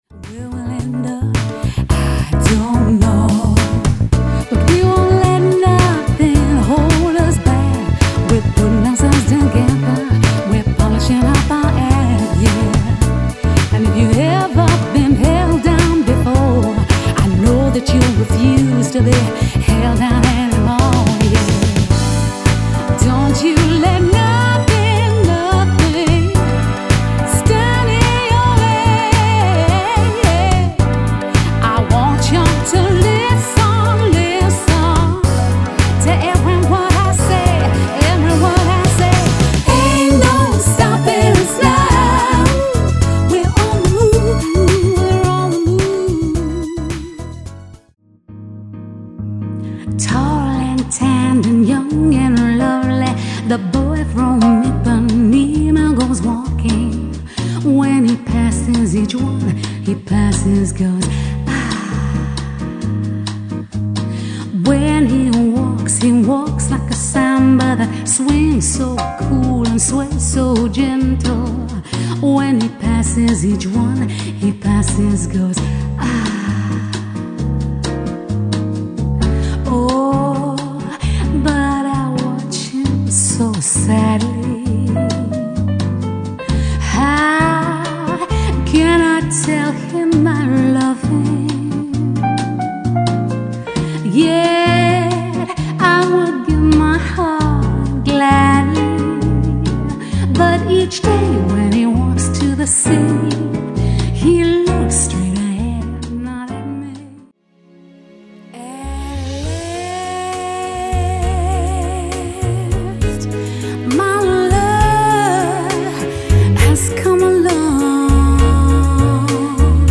With a passion for soul and jazz